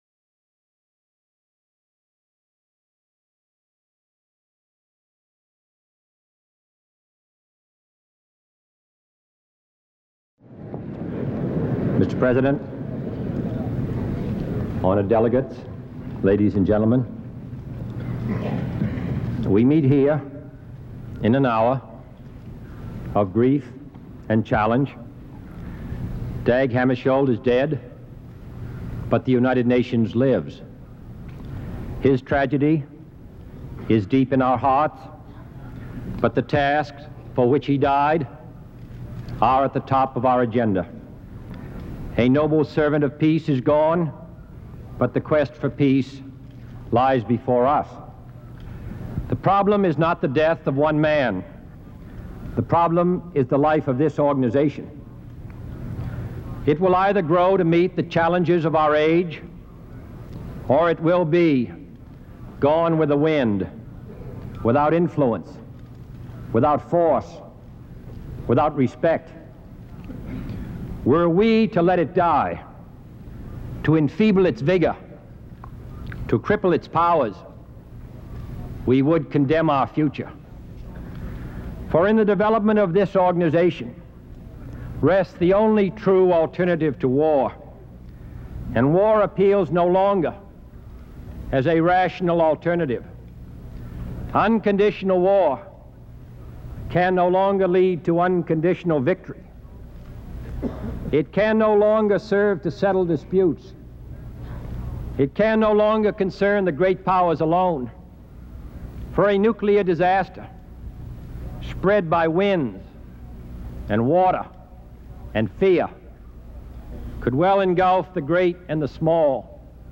Address to the United Nations General Assembly by John F. Kennedy on Free Audio Download
JohnFKennedyAddresstotheUnitedNationsGeneralAssembly.mp3